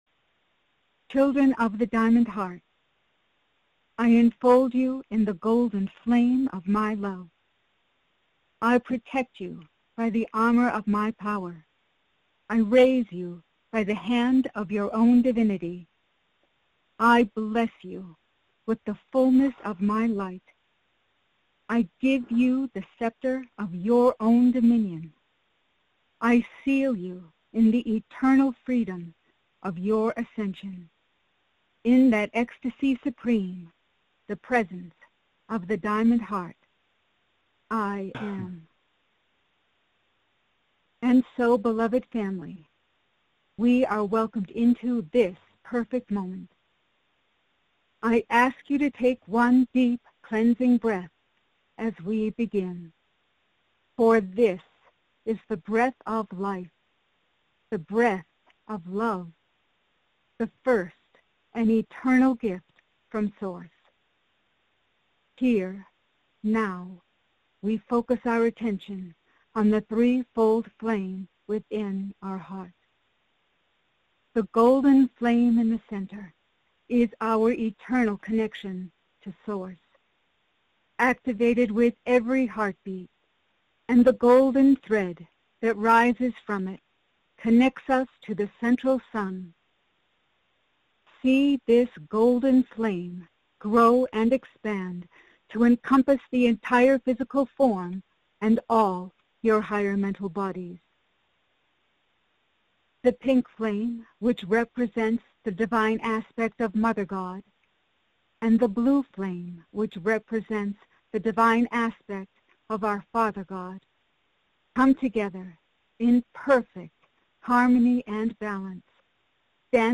Audio Recording Mediation – Minute (00:00) Follow along in group meditation with Lord Sananda.